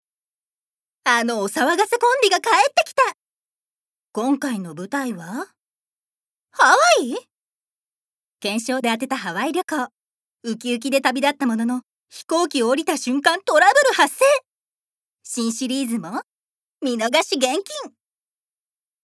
ボイスサンプル
ナレーション２